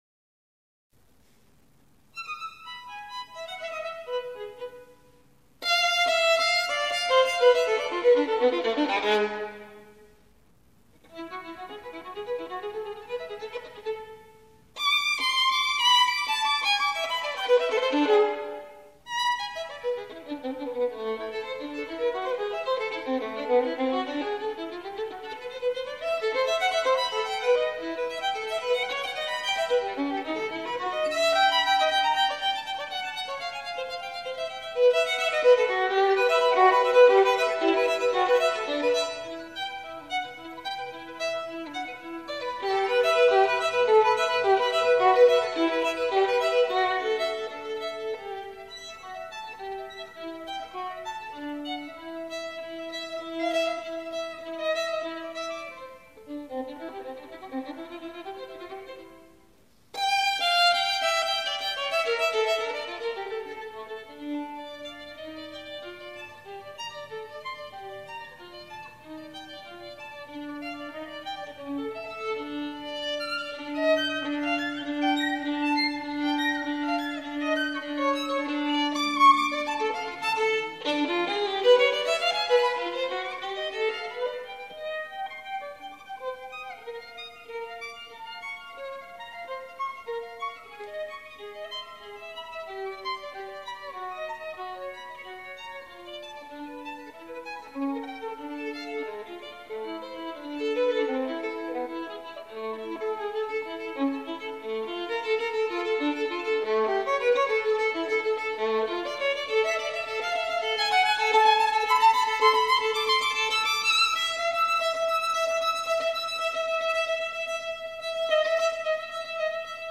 Violoniste
violon